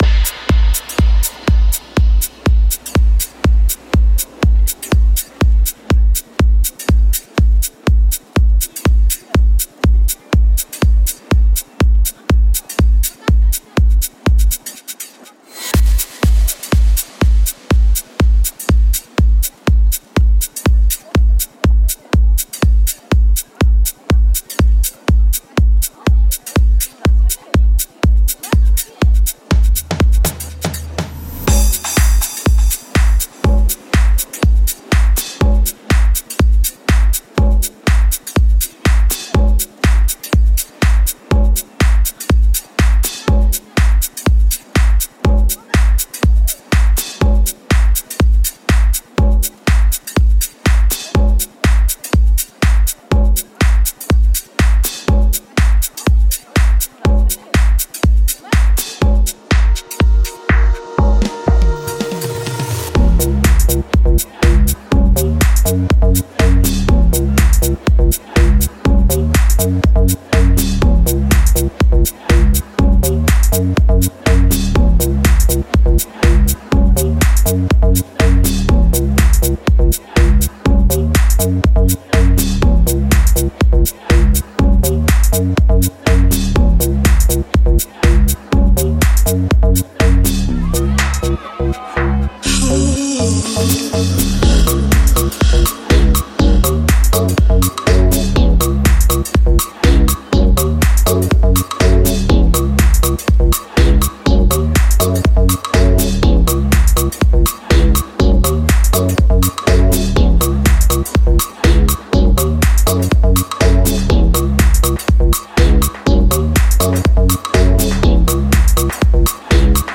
his take on the modern house sound.